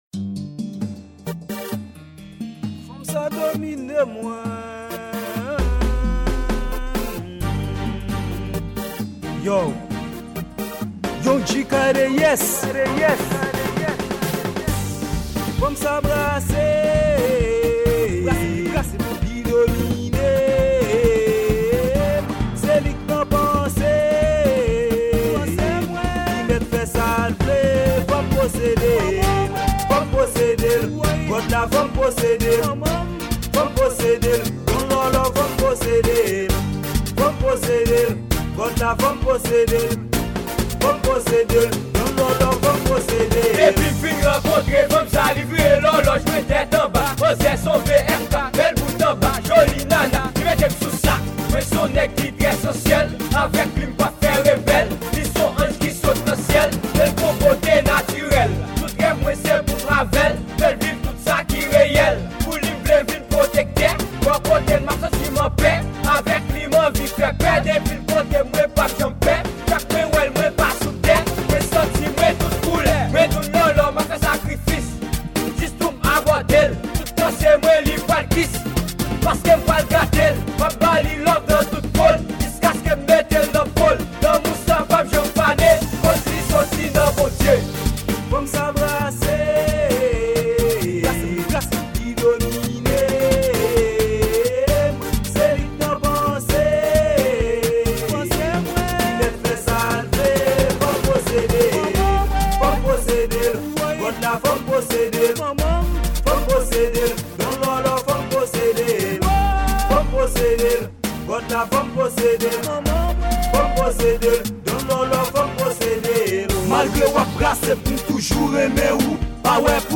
Genre: RRAP.